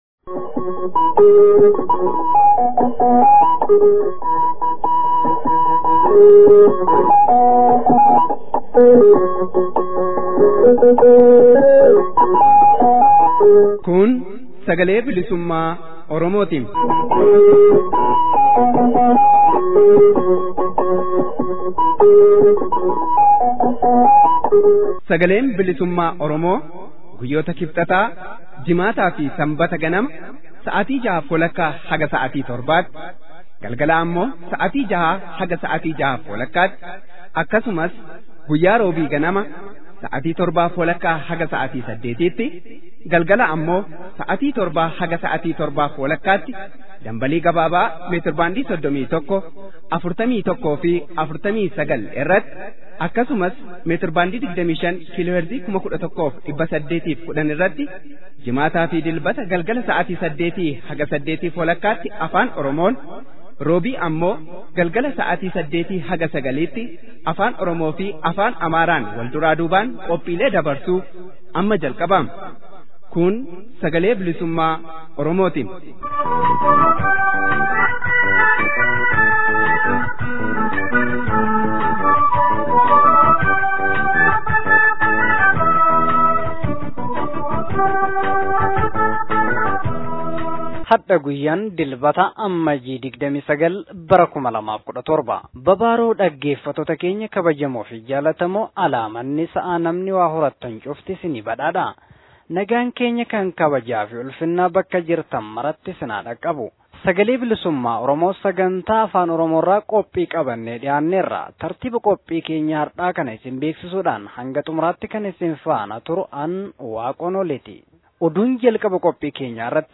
SBO: Amajjii 29 bara 2017. Oduu, Gabaasa Sirna kabajaa Guyyaa WBO Somaalilaandi fi Puuntilaand, Akkasumas Haasawa Ka’imman Oromoo: Ameerikaa -Menesootaarraa Guyyaa WBO ka 2017 Kabajuuf Jiddugala Mooraa Leenjii ABOtti Argaman Taasisan.